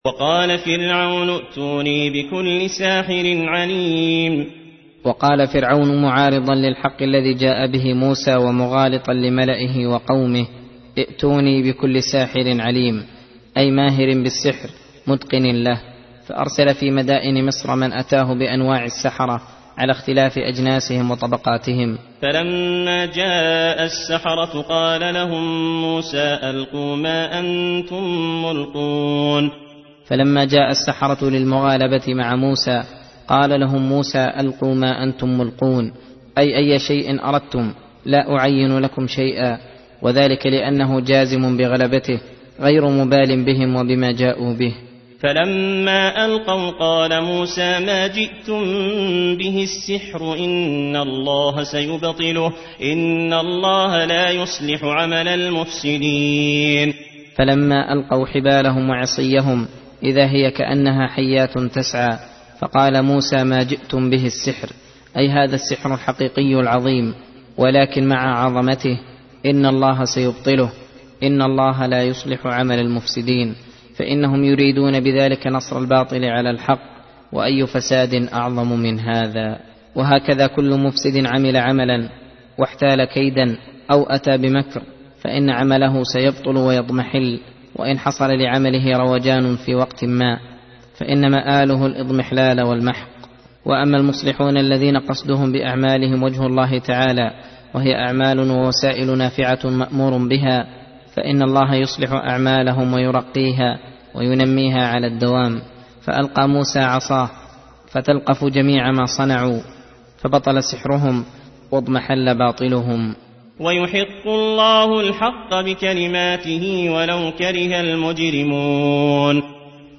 درس (29) : تفسير سورة يونس : (79 - 94)